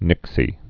(nĭksē)